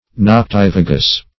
Meaning of noctivagous. noctivagous synonyms, pronunciation, spelling and more from Free Dictionary.
noctivagous.mp3